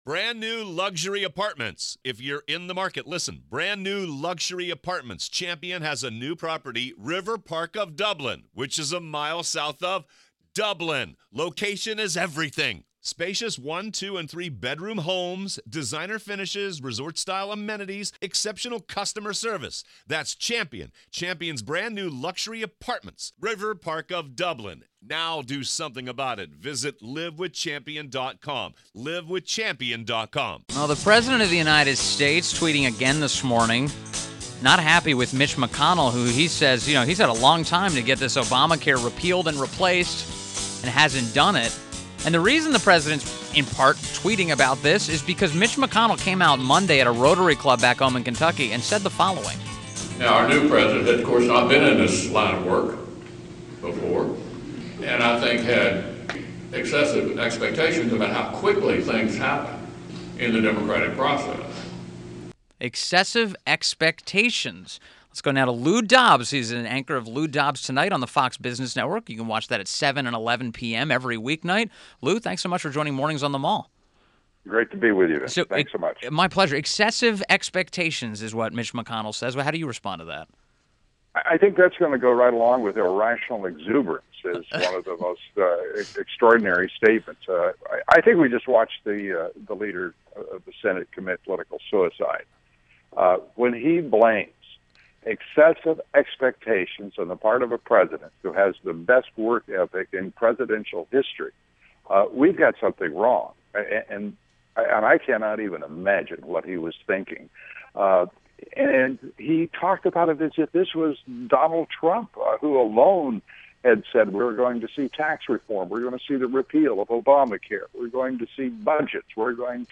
WMAL Interview - LOU DOBBS - 08.10.17
INTERVIEW — LOU DOBBS – anchor of “Lou Dobbs Tonight” on Fox Business Network, at 7 & 11 pm